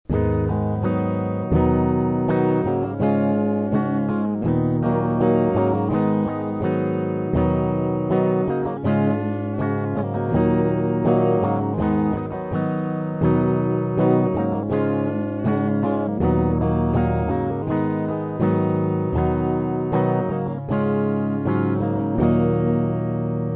Проигрыш (Am - C - D - Em):